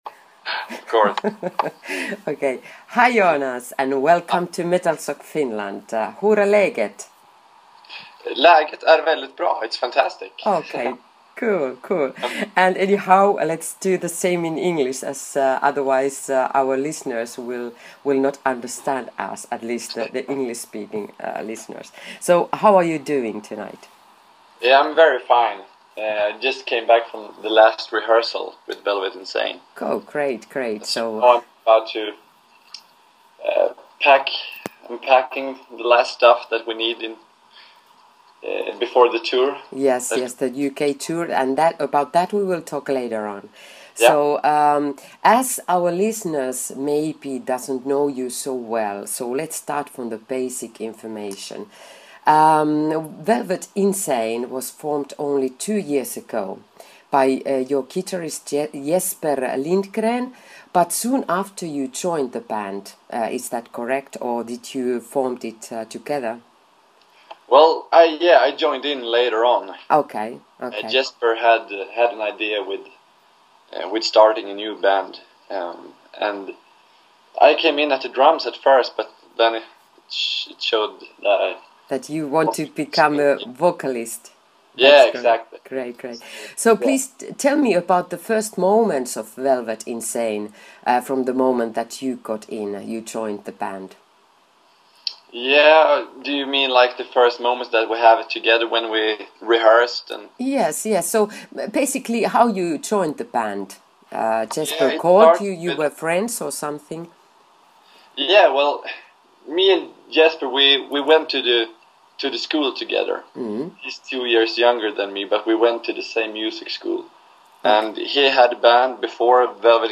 Audio Interview With VELVET INSANE